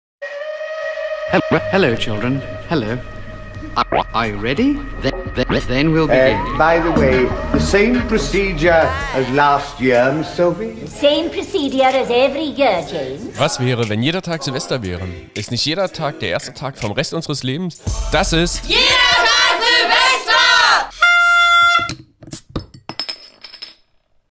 Intro Podcast